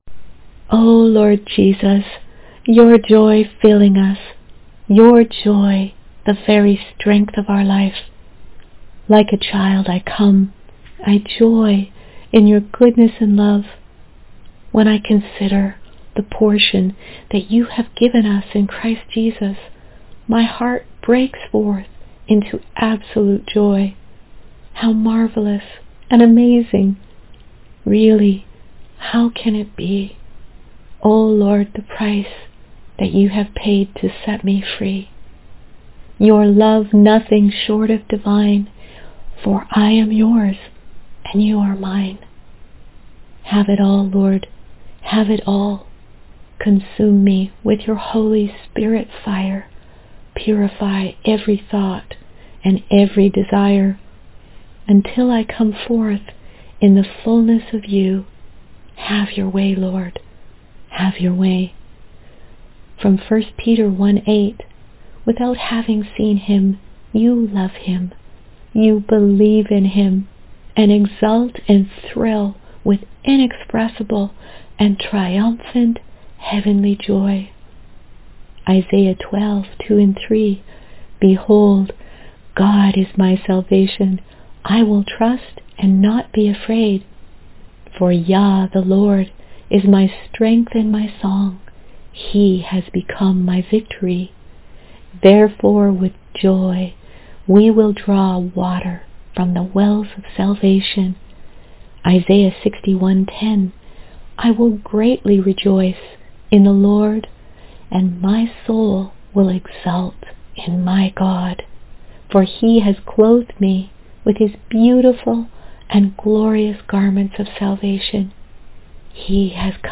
Prayer